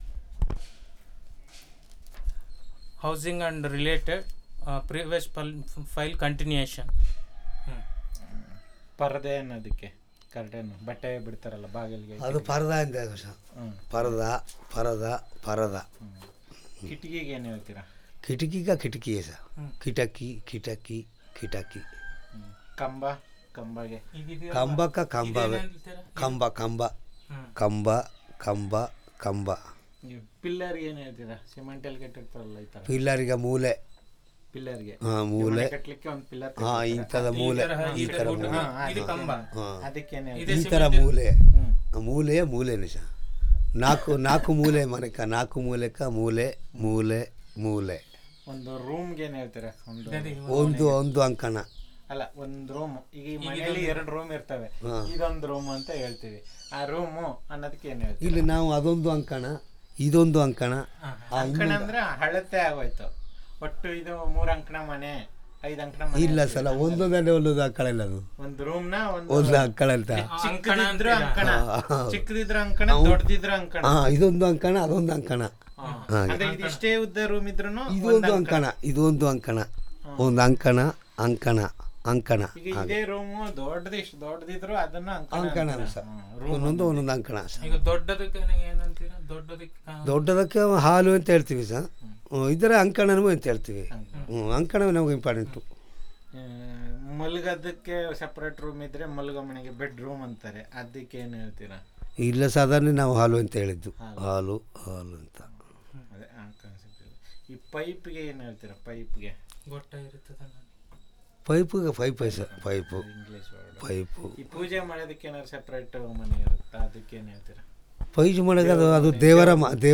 Elicitation of words about Housing and related